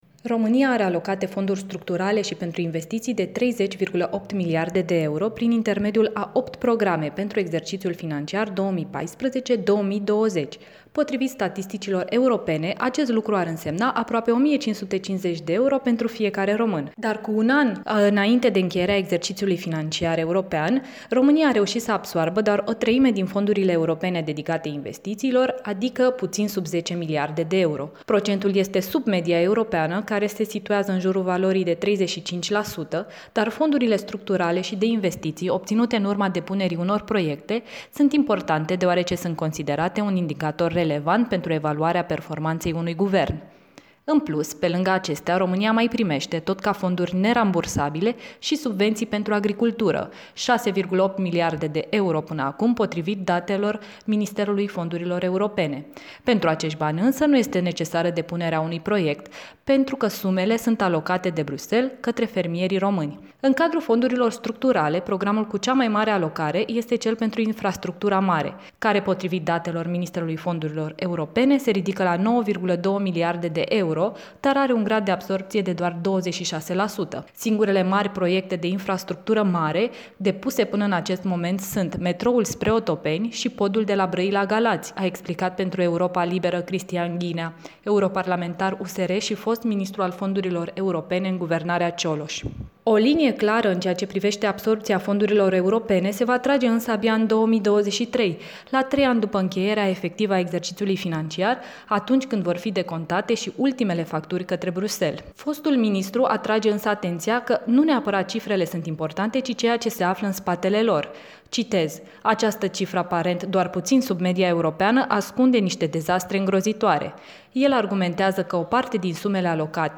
Din lipsă de proiecte mari, mai ales în domeniul transporturilor, declară Europei Libere fostul ministru al Fondurilor Europene, Cristian Ghinea.